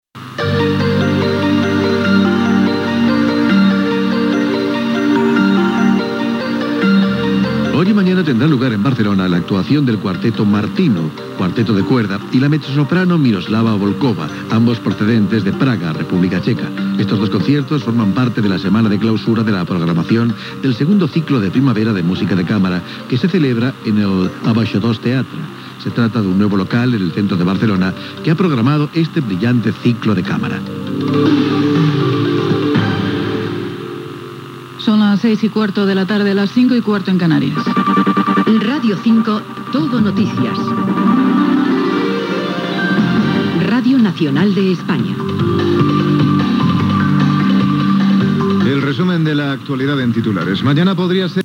Actuació del Quartet Martinu. Indicatiu de l'emissora.
Informatiu